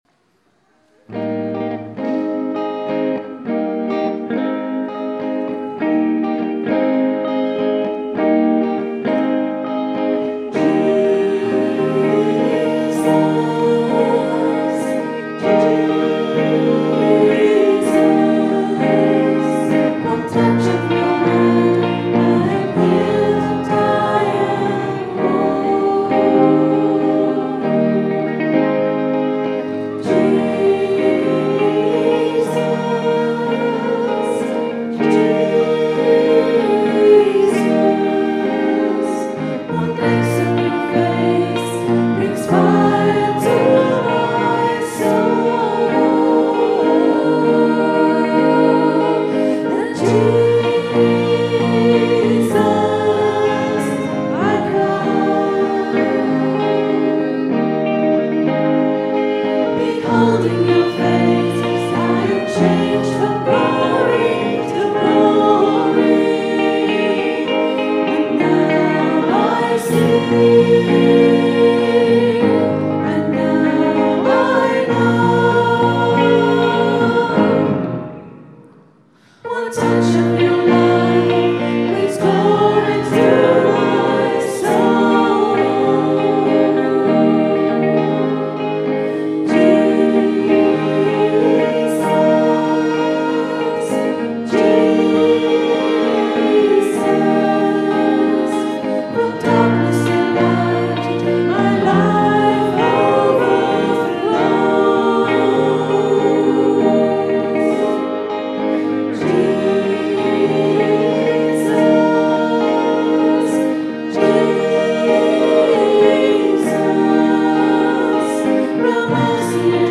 Recorded at 10am Mass on Sunday, 1st July, 2012.